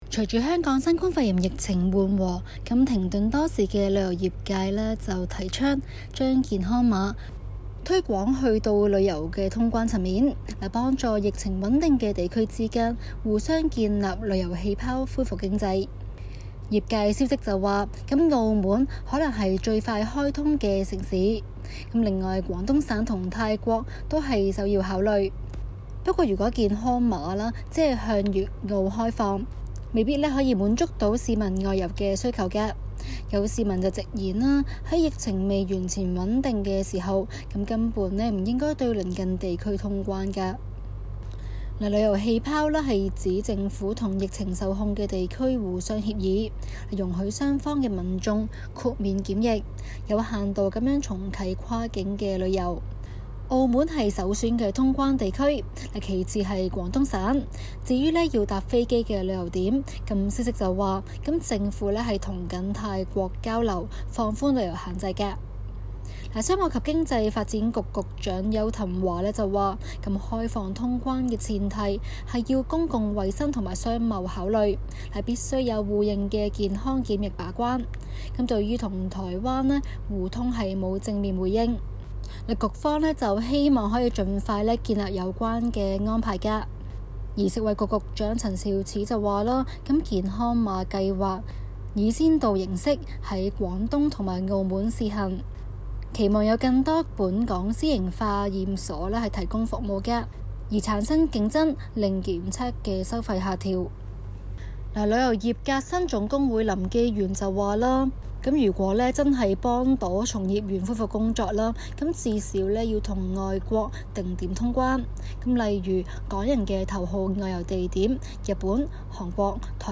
今期 【中港快訊 】環節重點報導香港旅游業提出新意，嘗試打破疫情對該行帶來的困局。